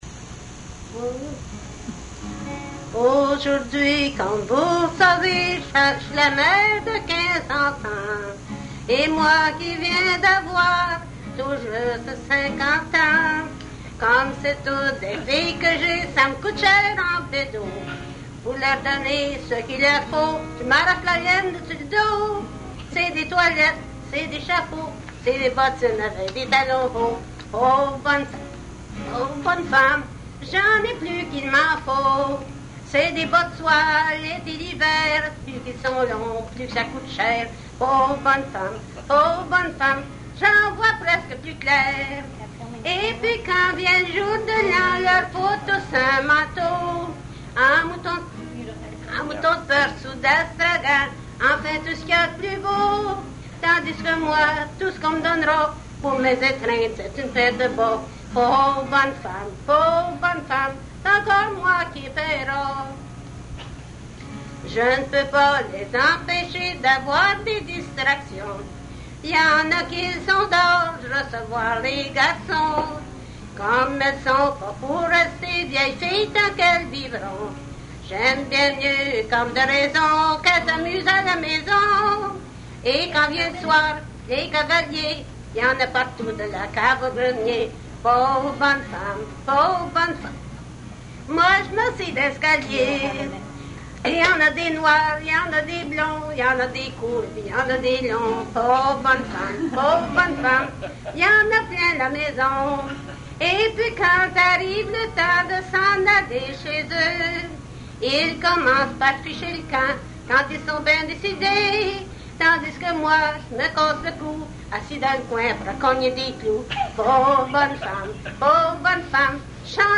Folk Songs, French--New England
Excerpt from interview